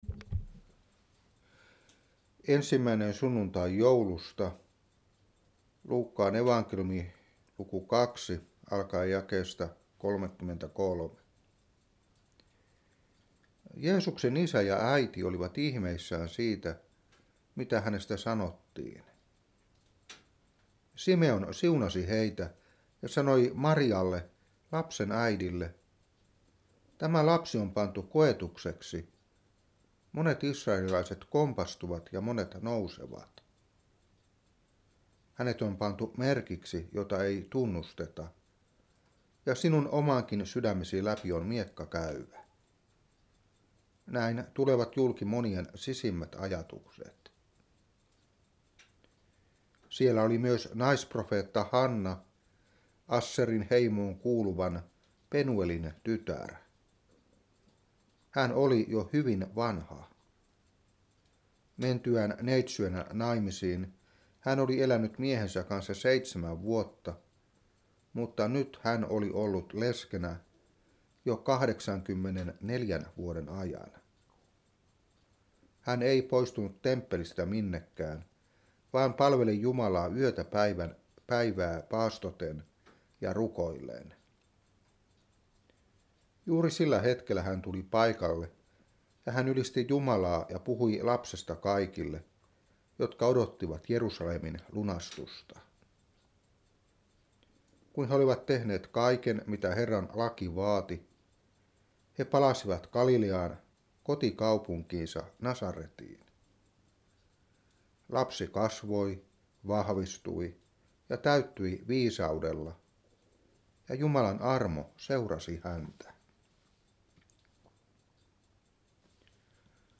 Saarna 2017-12.